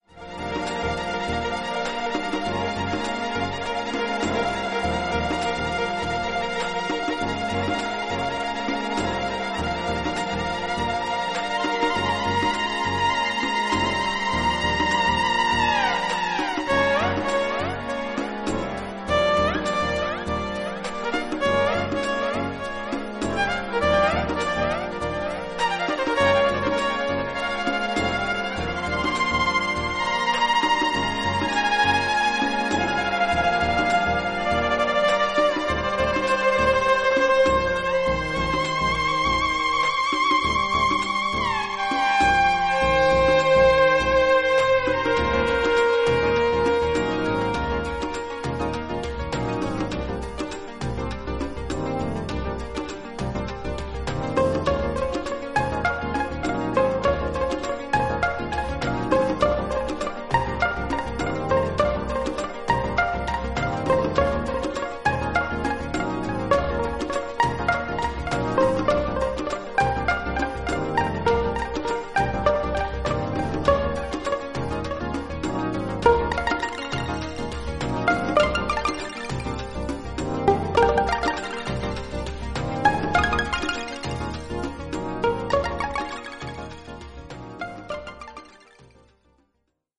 トロピカルなシーケンス・リズムとリヴァーブの効いたヴァイオリンに陶然とさせられるバレアリックな